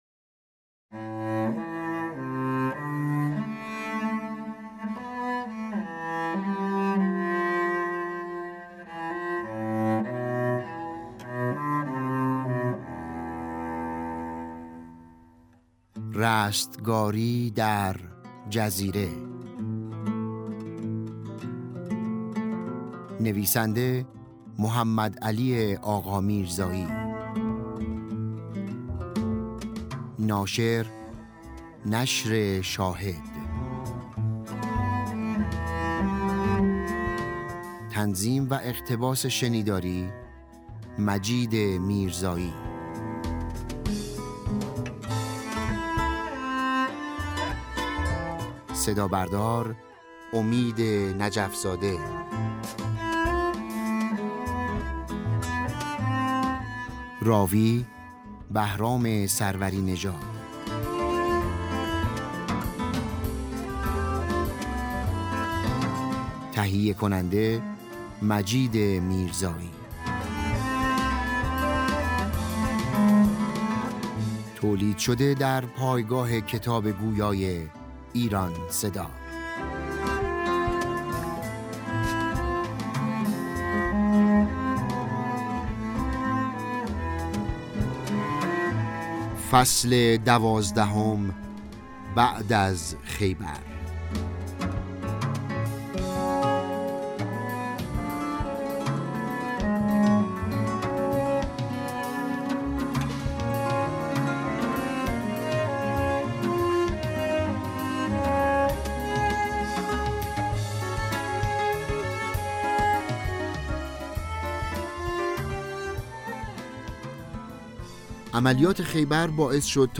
نسخه رایگان کتاب صوتی « رستگاری در جزیره» منتشر شد